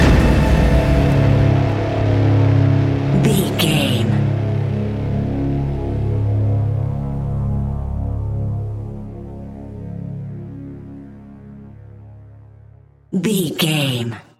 Fast paced
Thriller
Ionian/Major
C♭
dark ambient
synths